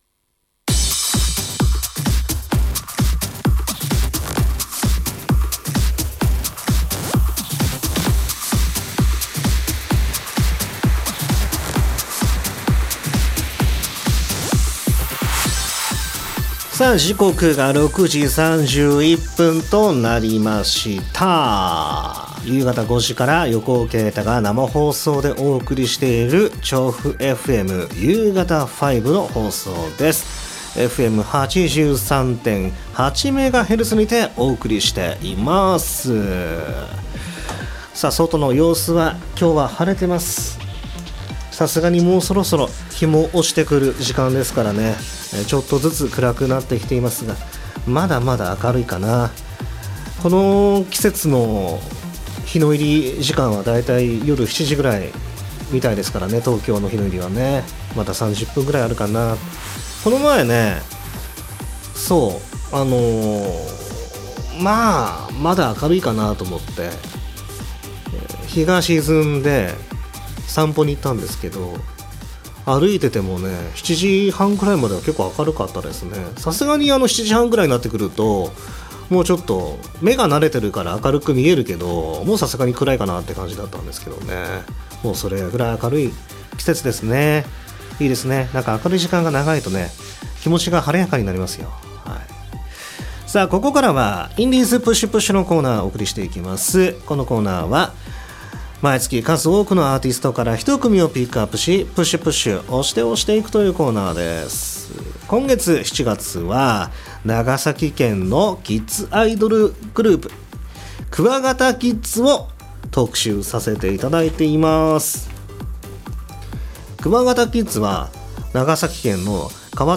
長崎県の川棚町教育委員会、オオクワガタ研究会とハッピーヴォイス事務所がコラボで結成したキッズアイドルユニット。